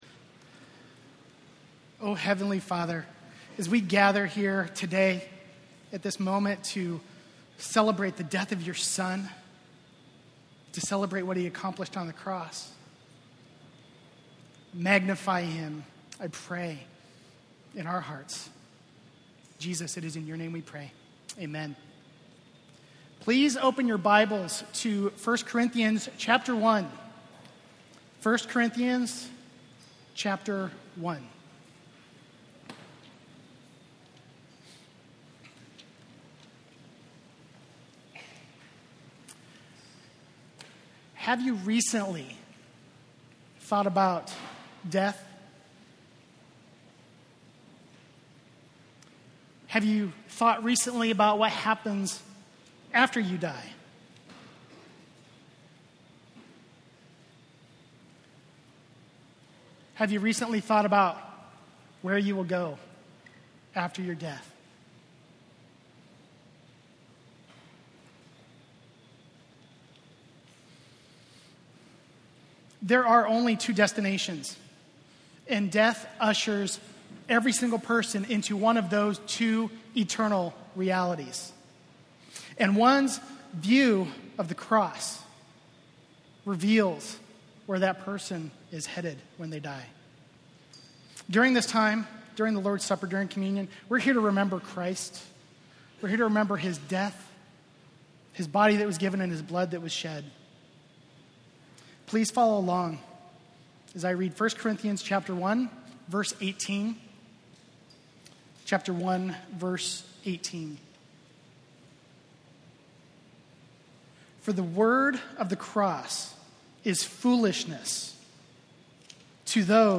October 26, 2014: Communion Meditation - Grace Bible Church